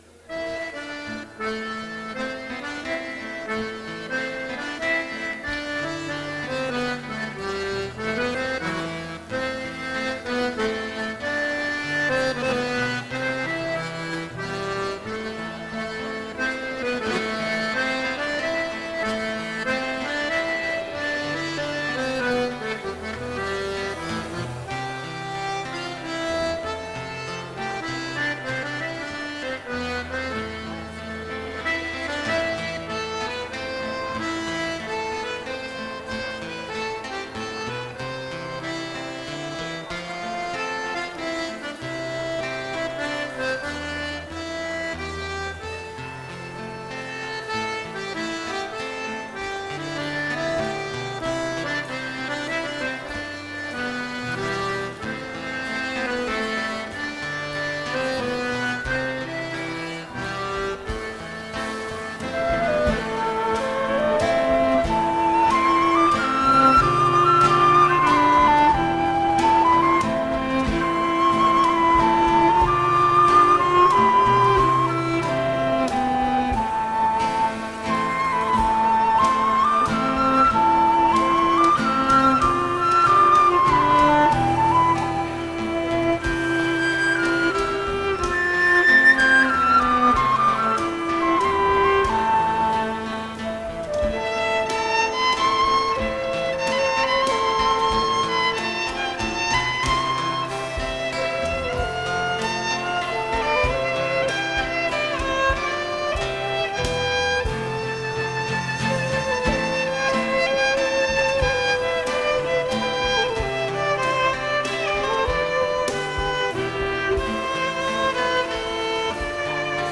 Voici un enregistrement du morceau présenté précédemment, joué en concert-bal par mon groupe Draft Company. Moment de calme entre deux danses..
Slow air